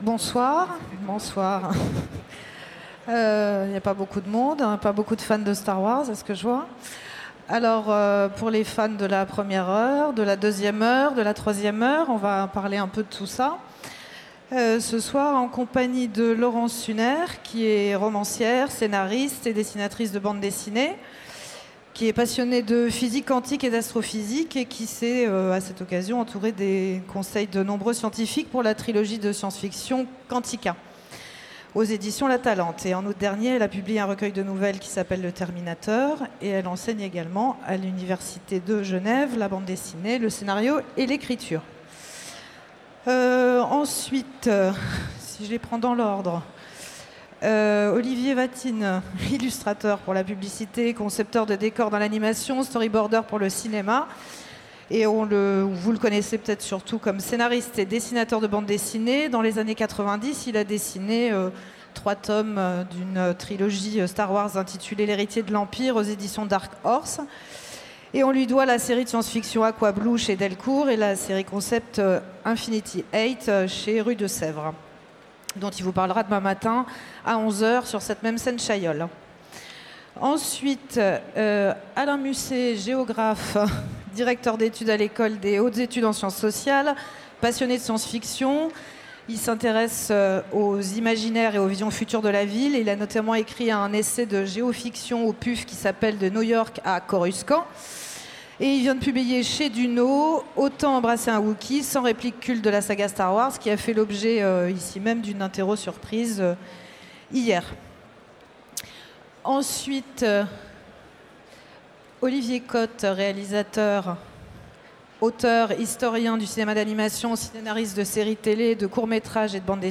Utopiales 2017 : Conférence Les 40 ans de Star Wars